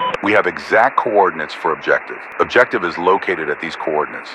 Radio-commandObjectiveCoordinatesPrecise.ogg